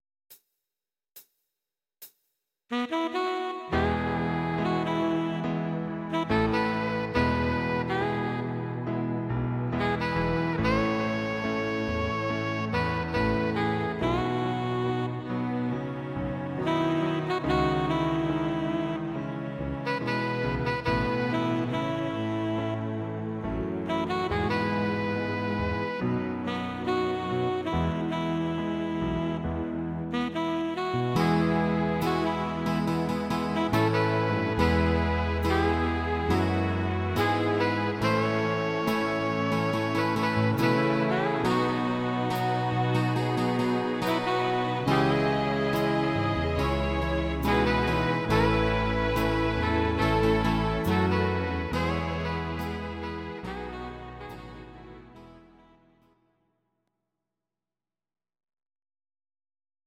Audio Recordings based on Midi-files
Pop, Duets